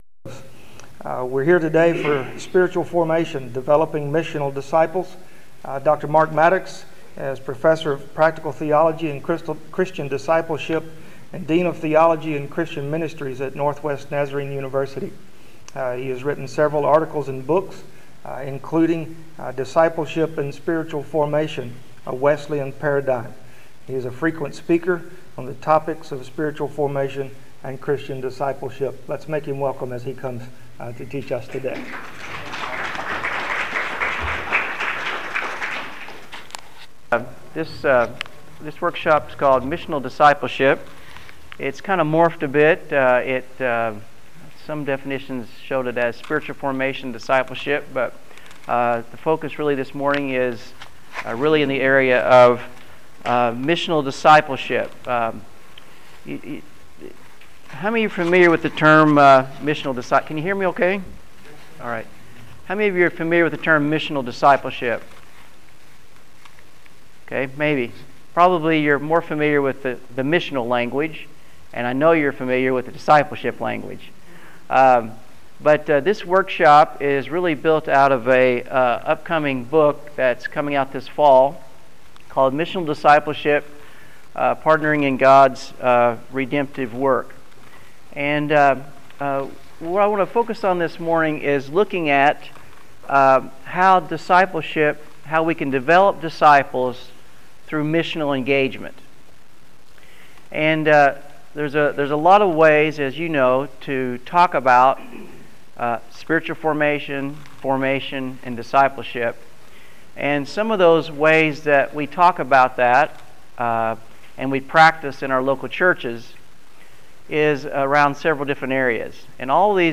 General Assembly 2013--Spiritual Formation: Developing Faithful Disciples